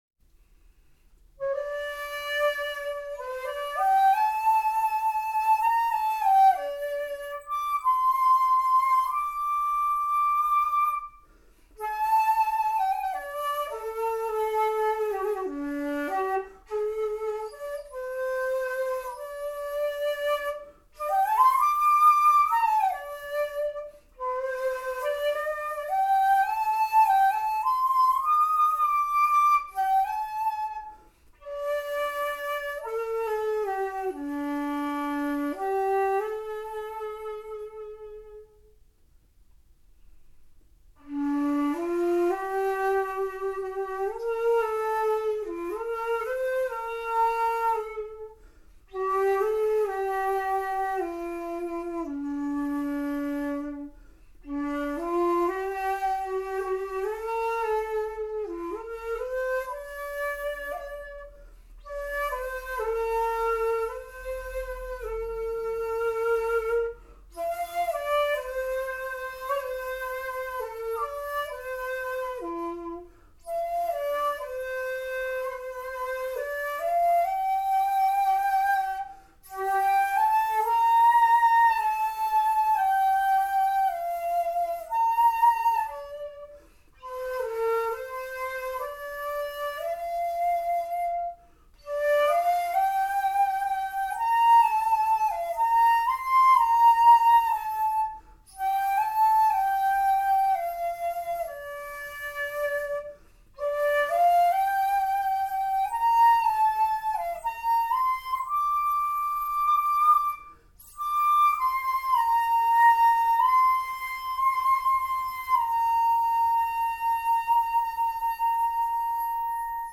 ○現代曲・・・地塗り管
（必要条件）音程が安定している
比較的大きな音が必要